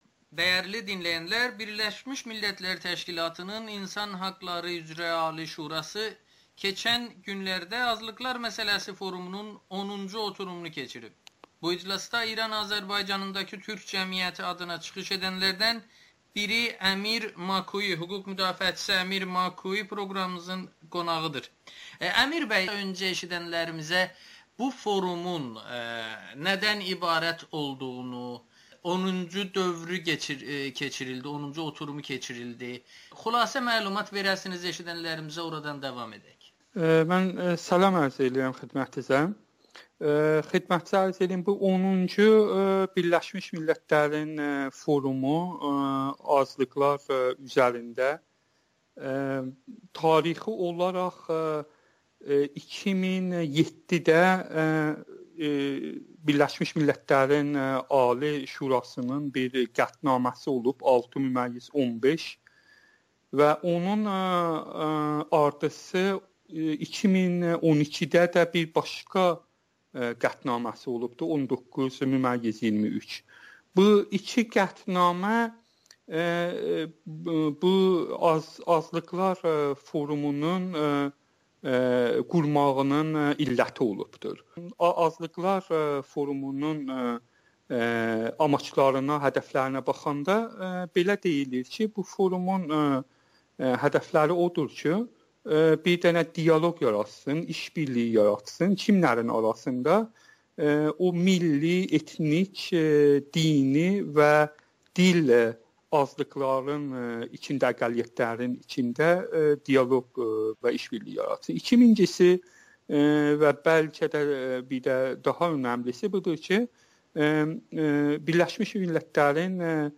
BMT-nin azlıqlar forumunda İran türklərinin geniş iştirakı oldu [Audio-Müsahibə]
Güneyli hüquq müdafiəçisi Amerikanın Səsinə danışır